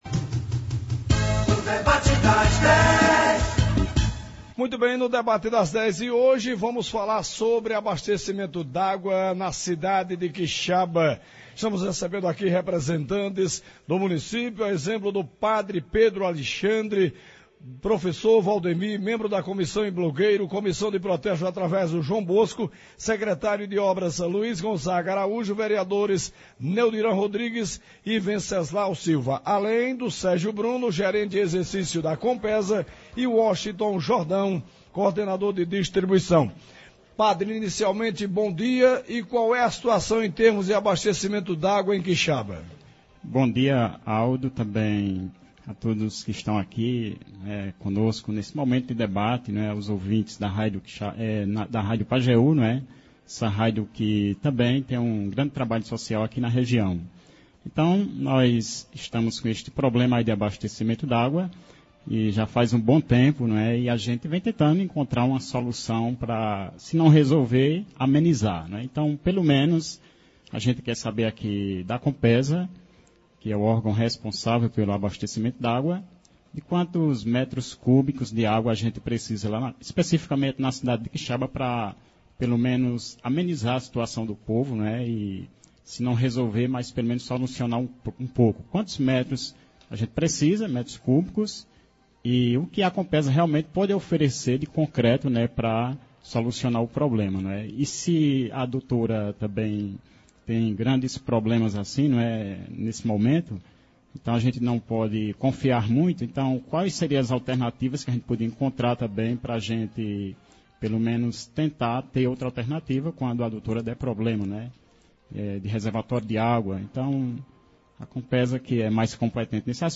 Hoje (14) nos estúdios da Pajeú